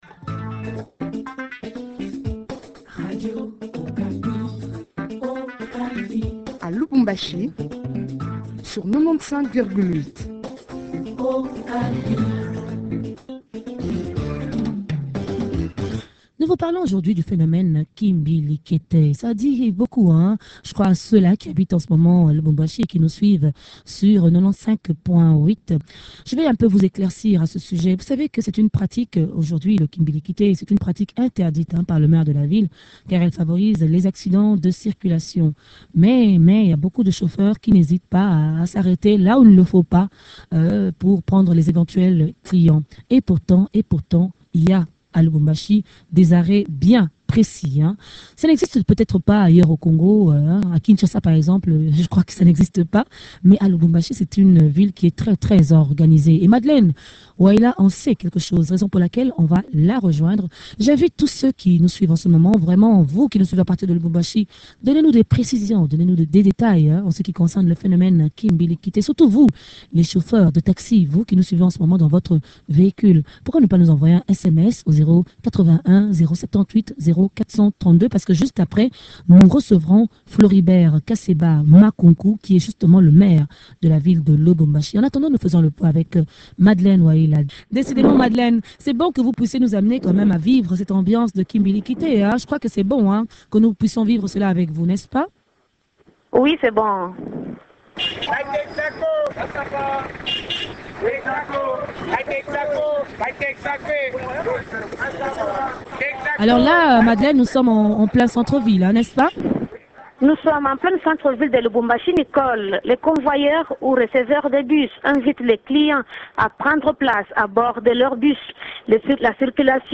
recoit Floribert Kaseba Makunku, maire de la ville de Lubumbashi